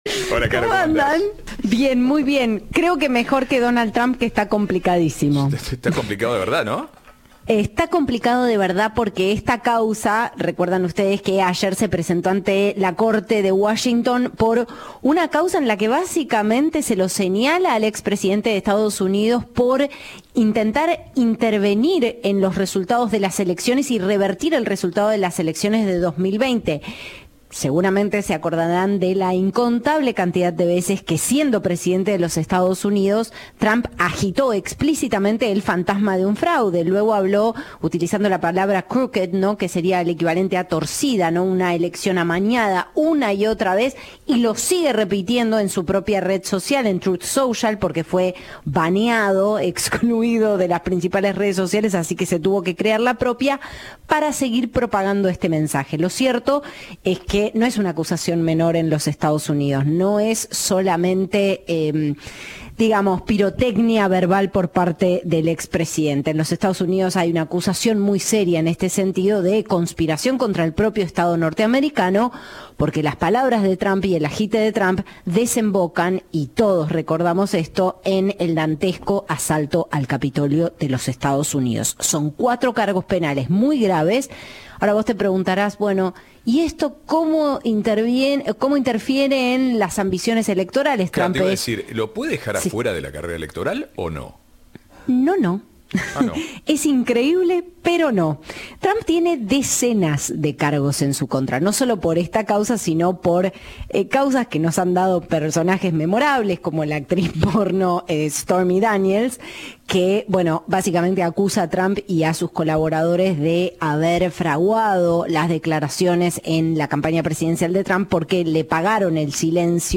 Informe en "Ahora País".